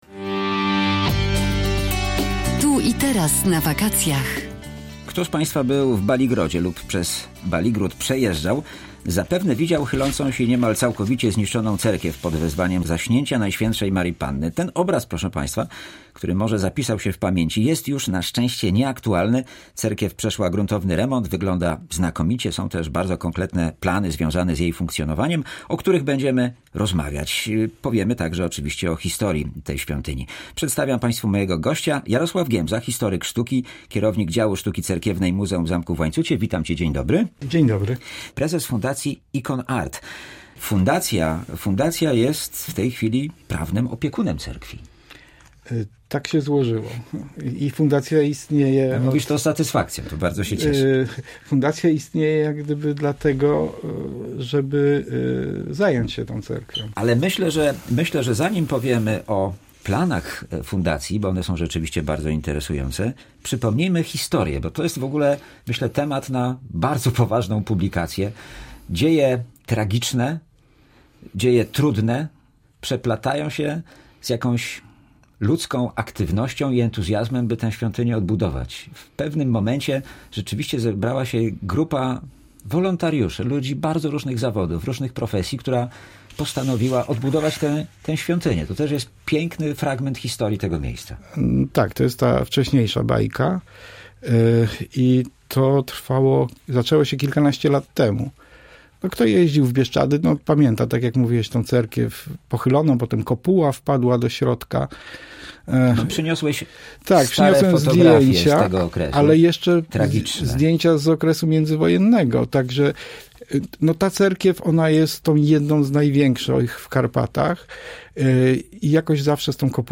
Gościem dzisiejszej audycji Tu i Teraz na wakacjach był historyk sztuki